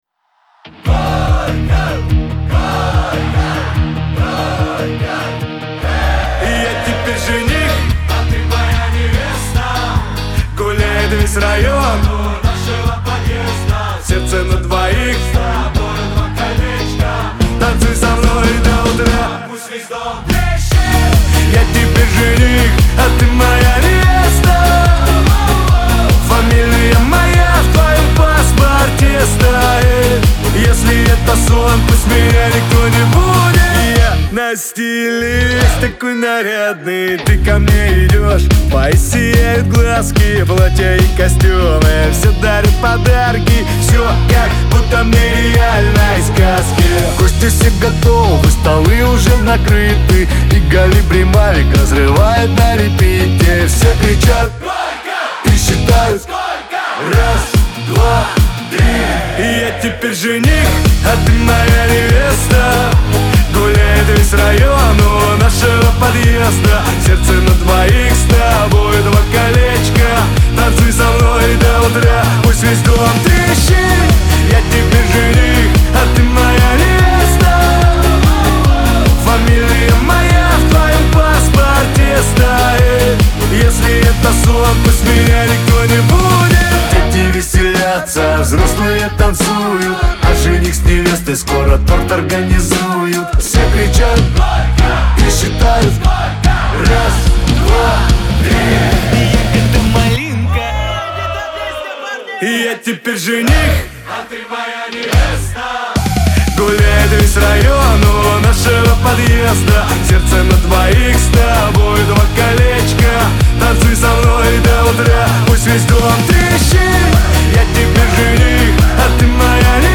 Веселая музыка
pop
диско , танцевальная музыка
дуэт